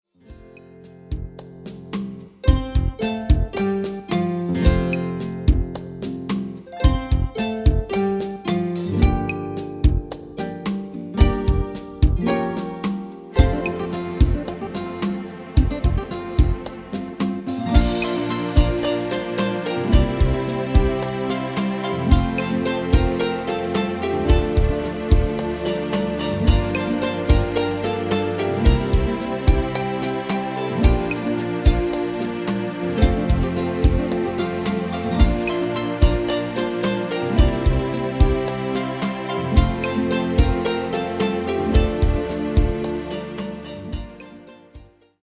Demo/Koop midifile
Genre: Ballads & Romantisch
Toonsoort: F
Demo's zijn eigen opnames van onze digitale arrangementen.